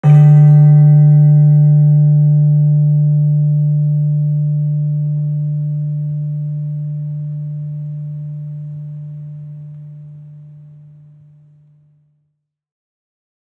HSS-Gamelan-1
Gender-2-D2-f.wav